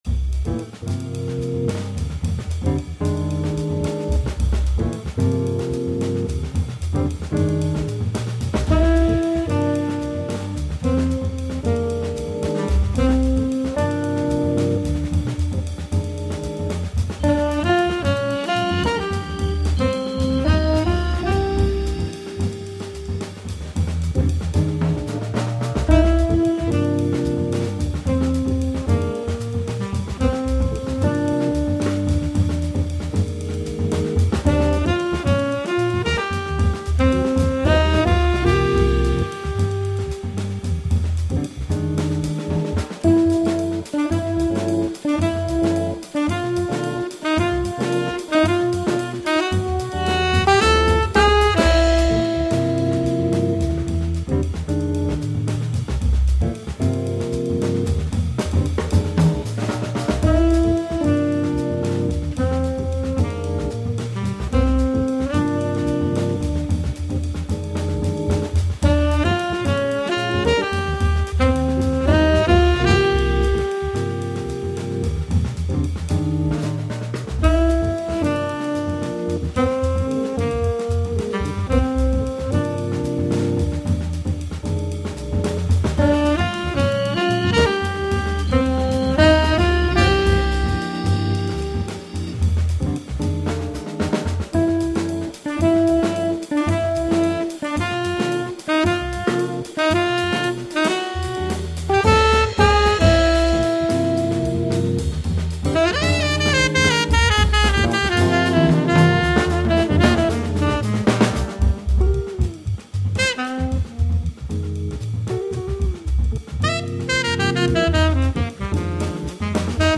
tenor sax
Viscount Legend, Leslie 3300
l'a une bonne main gauche le gars !!!!